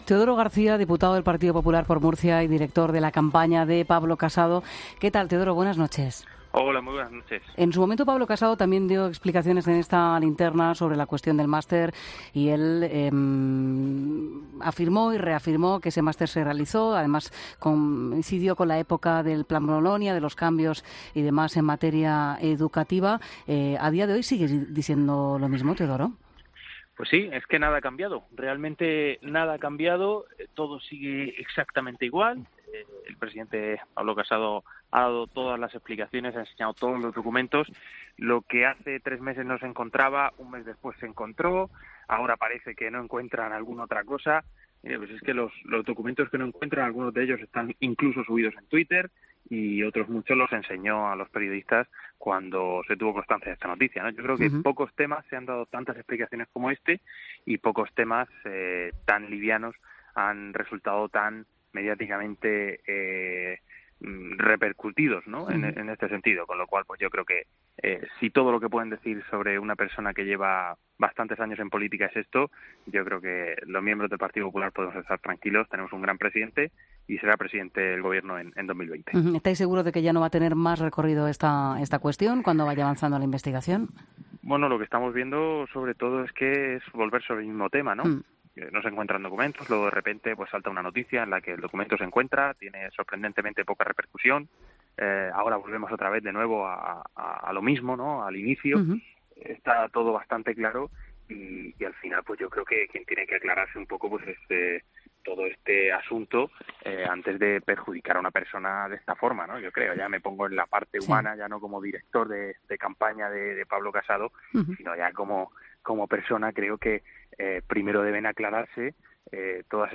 El director de campaña de Pablo Casado, Teodoro García, se ha mostrado tranquilo en 'La Linterna' tras las últimas informaciones sobre el máster del...